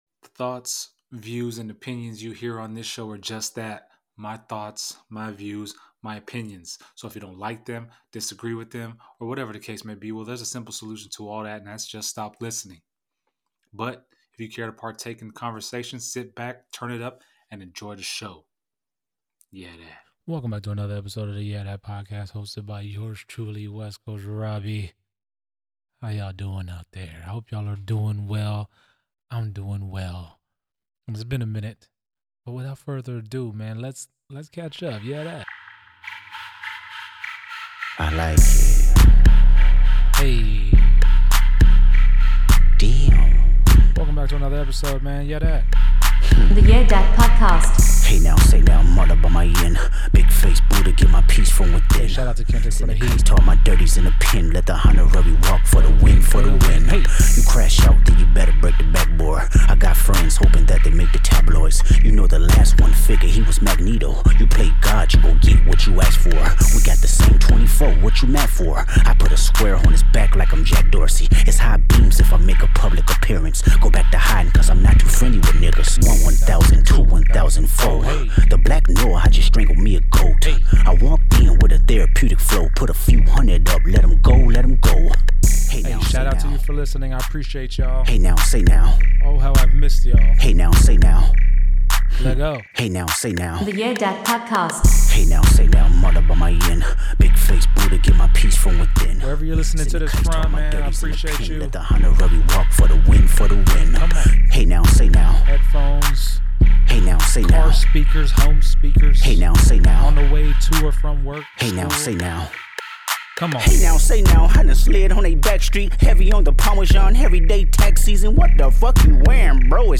Background Music
Intro Music